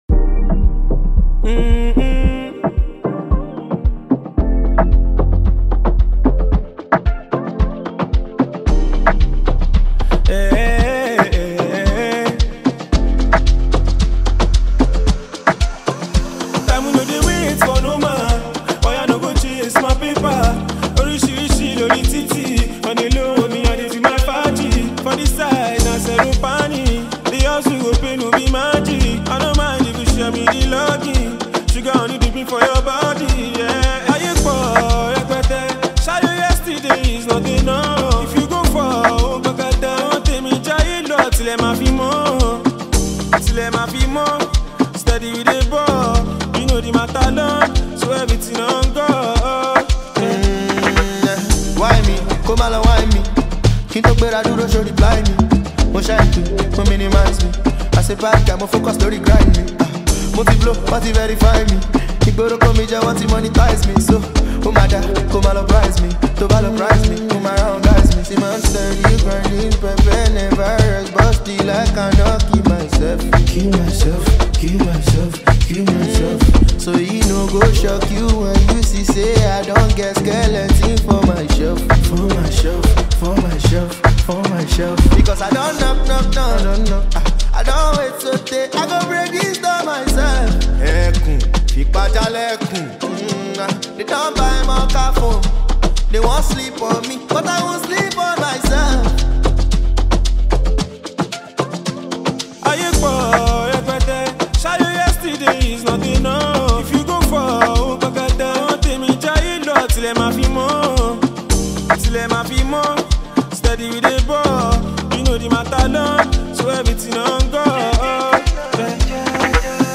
A REMARKABLE FUSION OF AFROBEAT VIBES